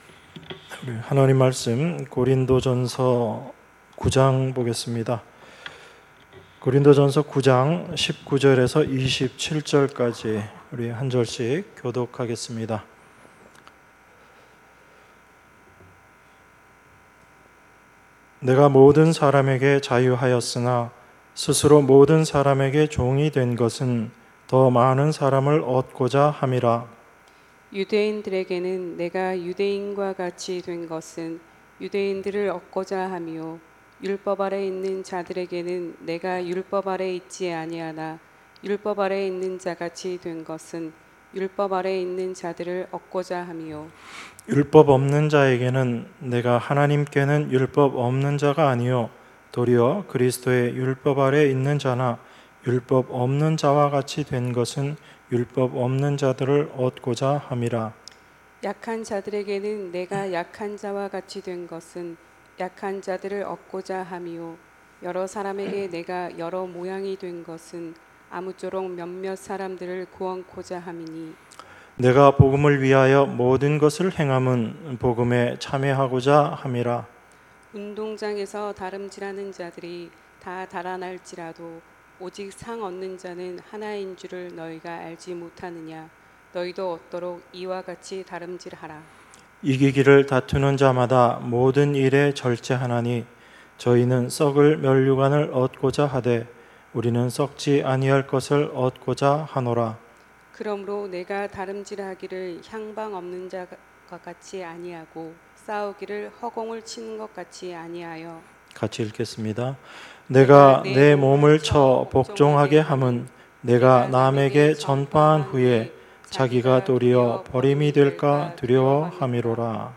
주일1부 고린도전서 9:19~27